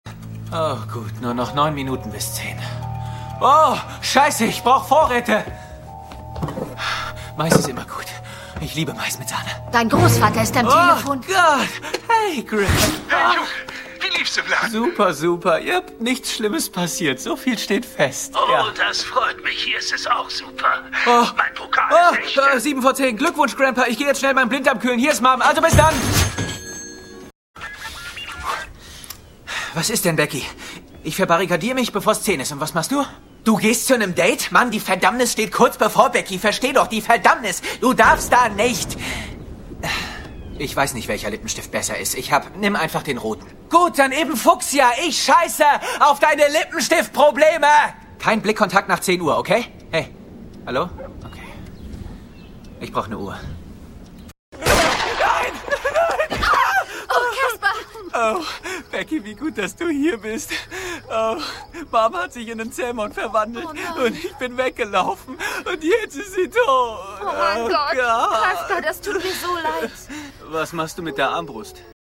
Jung, dynamisch, cool & souverän!
Sprechprobe: Sonstiges (Muttersprache):
young german voice over artist